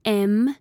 Звуки букв английского алфавита
Произношение четкое, без фоновых шумов.
Mm em